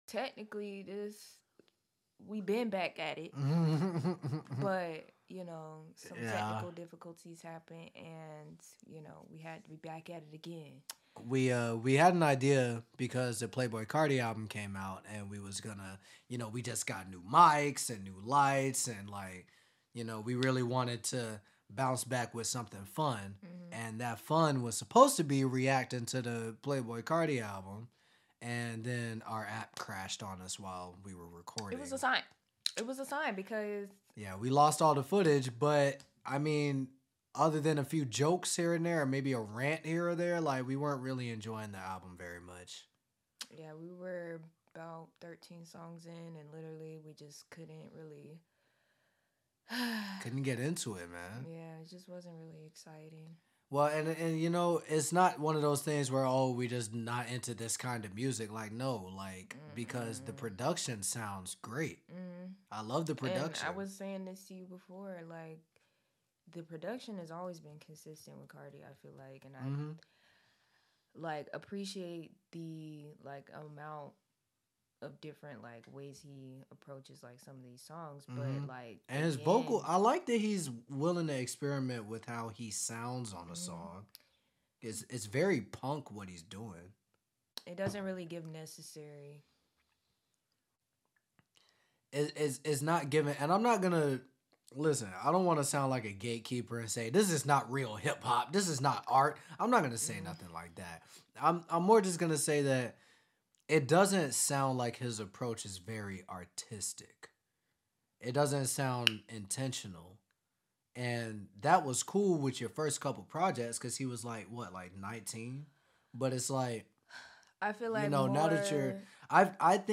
Album Review
***also please excuse the mic quality*** ------------------------------------------------------------------------------------------------------ Disclaimer This show is not to be taken solely as fact or truth.